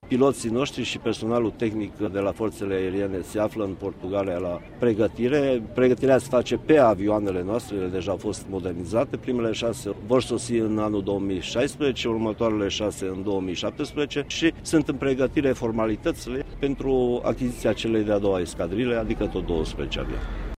El a făcut aceste afirmaţii la Sibiu, unde aproximativ 200 de soldaţi profesionişti au depus, ieri, jurământul.
Ministrul Apărării, Mircea Duşa, a mai anunţat şi că România intenţionează să mai cumpere o escadrilă de 12 avioane de luptă F16: